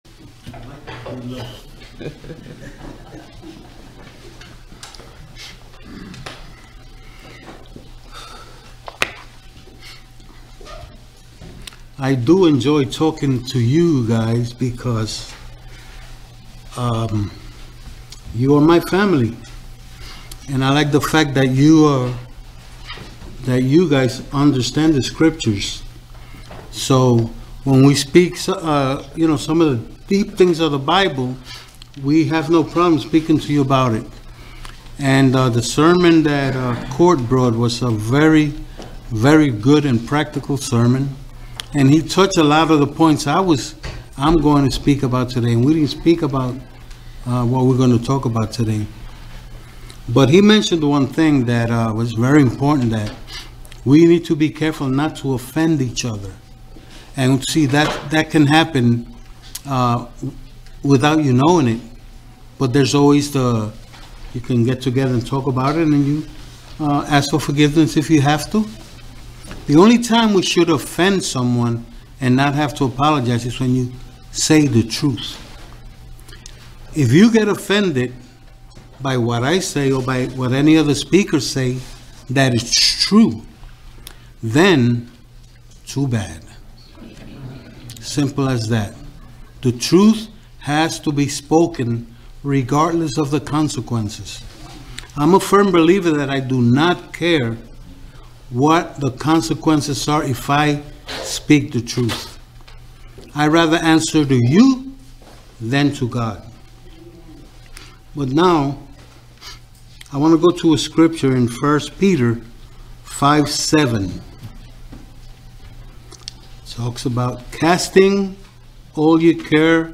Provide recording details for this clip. Given in Ocala, FL